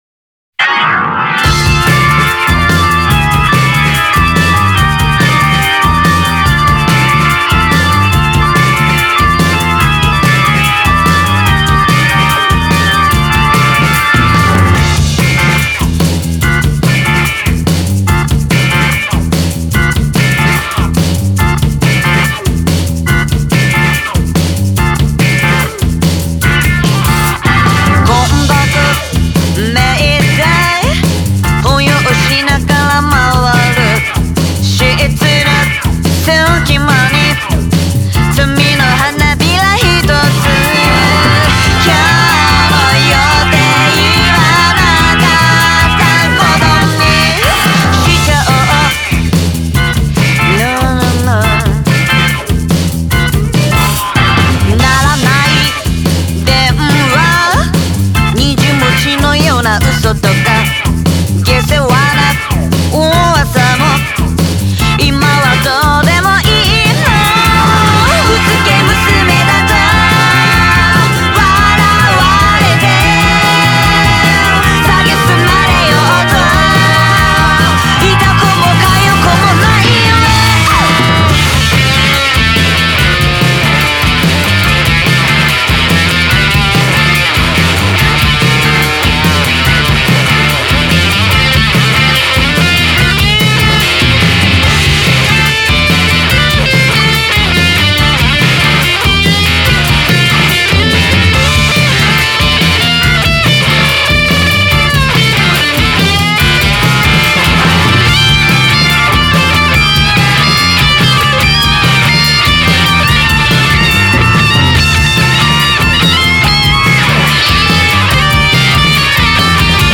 Genre: Psychedelic Rock, Garage